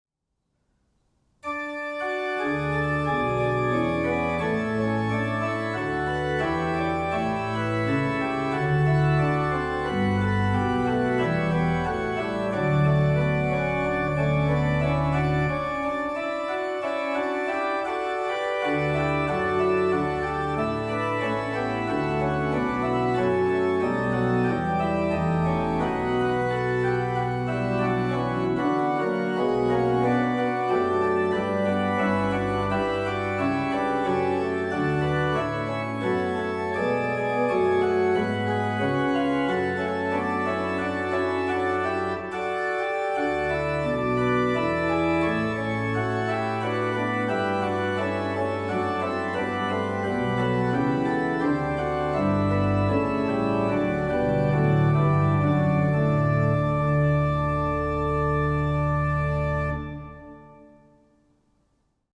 More organ pieces
J-S-Bach-Chorale-Prelude-for-Christmas-_Vom-Himmel-hoch-da-komm-ich-herr_-BWV606-.mp3